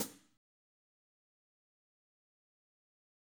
TC2 Live Hihat16.wav